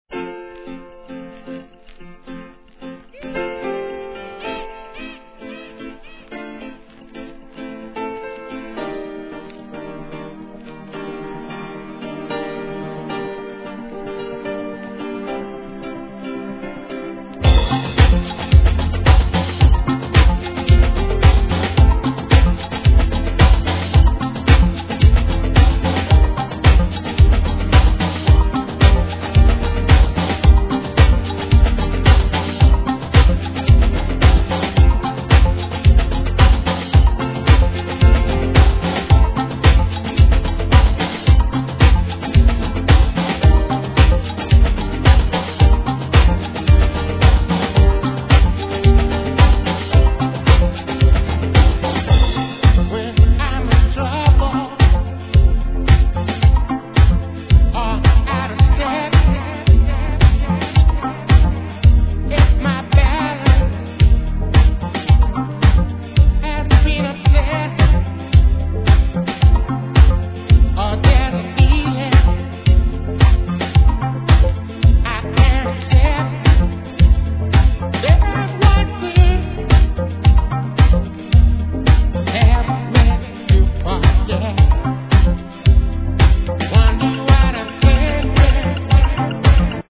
HIP HOP/R&B
メロ〜なダンス・ナンバー!